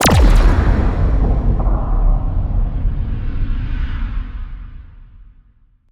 Explosion and footstep SFX
EXPLDsgn_Implode_15.wav